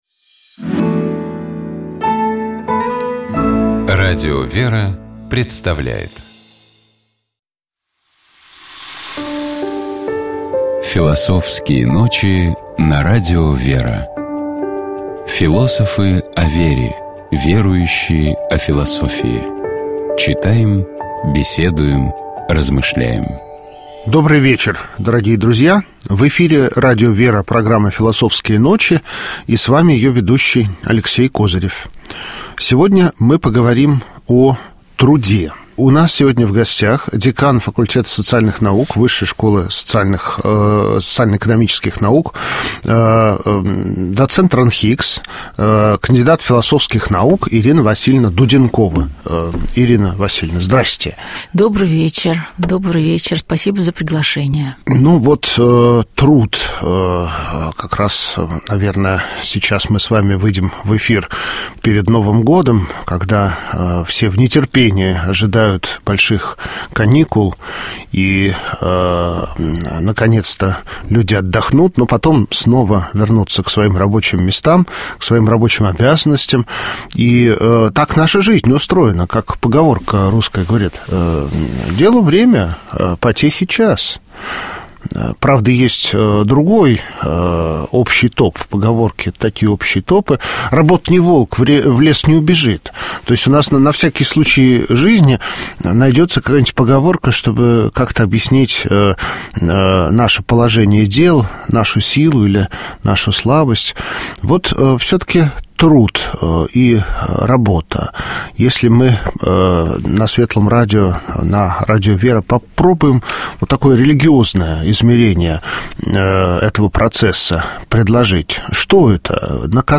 Философы о вере, верующие о философии. Читаем, беседуем, размышляем.
Гости нашей студии размышляют о том, как интеллектуальные гении разных эпох решали для себя мировоззренческие вопросы.